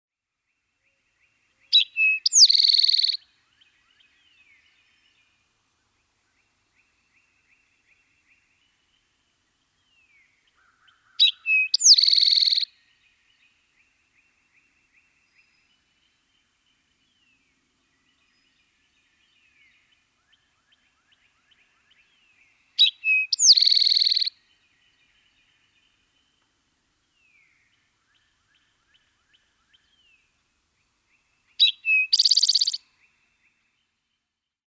Eastern Towhee
BIRD CALL: DESCRIBED AS “DRINK YOUR TEE-EE-EA” OR “SEE TOW-HEE-EE,” OFTEN GIVEN FROM HIGH, VISIBLE PERCHES.
Eastern-towhee-call.mp3